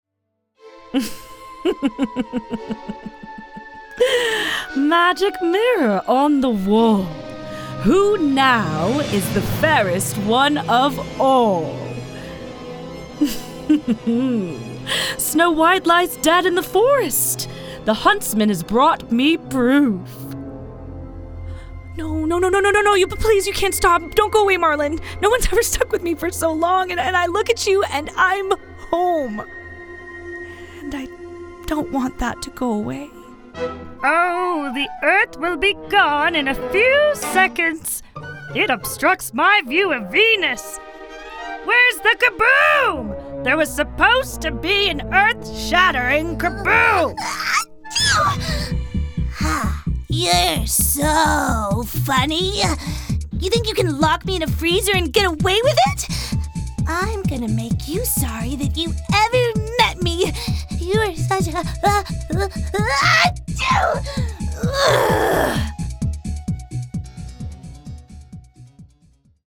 Voice-Over Reel